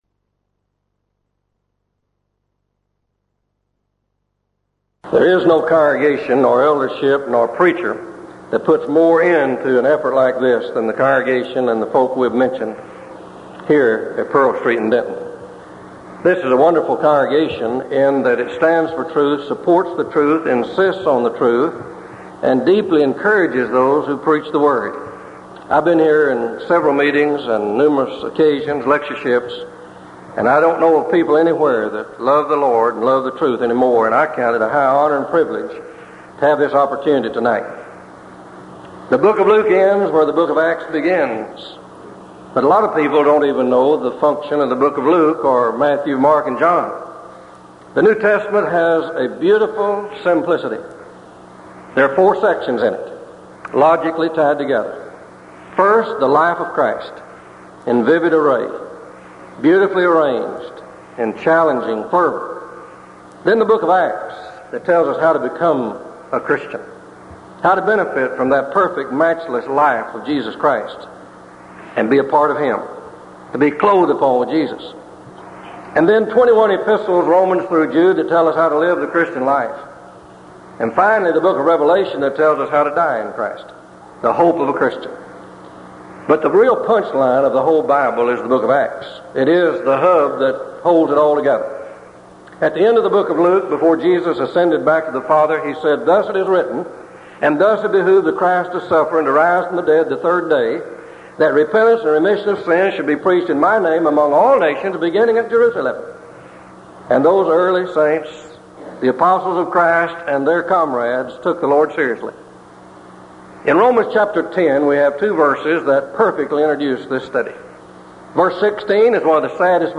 Denton Lectures
1985 Denton Lectures